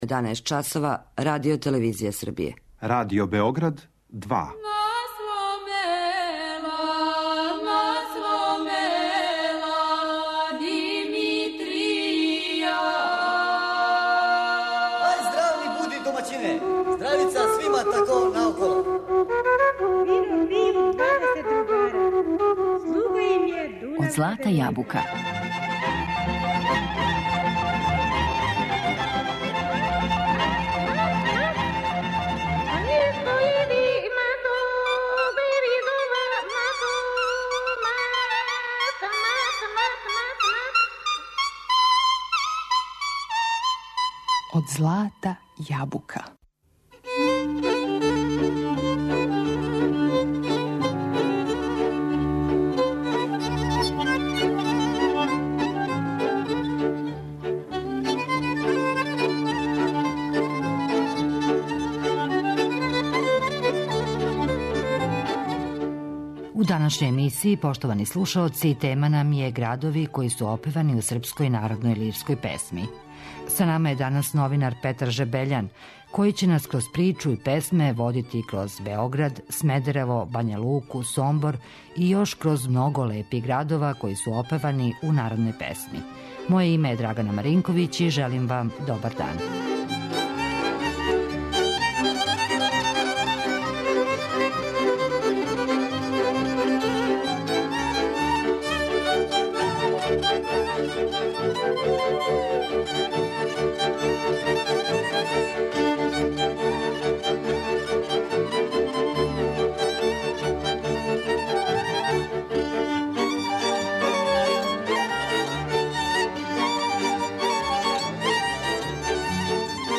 Кроз песму и причу, говорићемо о Београду, Смедереву, Бања Луци...